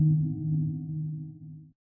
sonarTailAirFar2_Directional.ogg